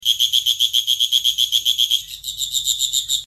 蝈蝈 | 健康成长
guoguo-sound.mp3